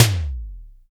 IMPCTTOM MD.wav